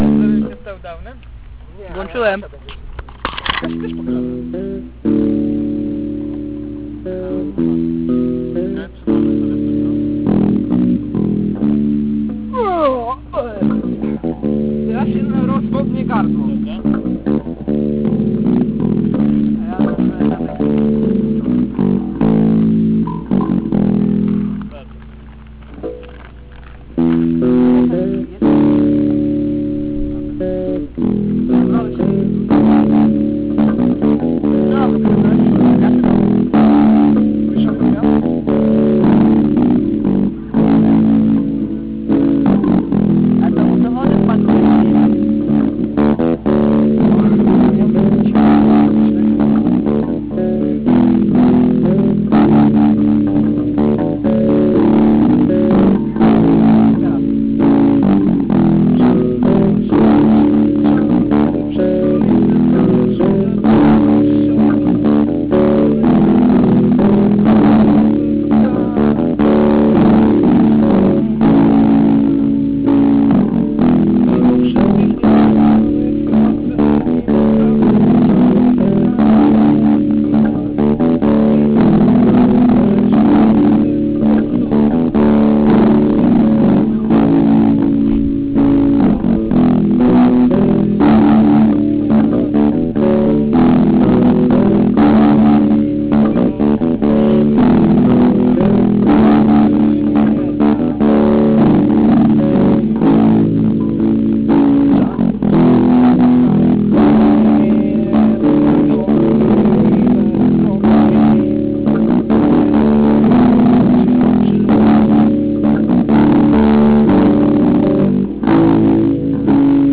zagrał po raz pierwszy w trzyosobowym składzie
gitara
wokal